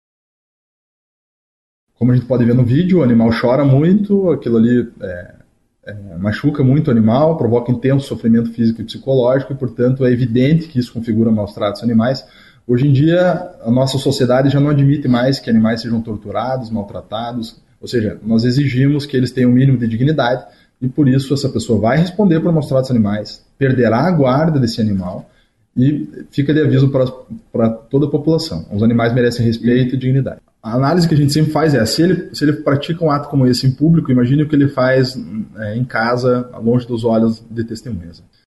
SONORA-AGRIDE-CACHORRO-02-BO.mp3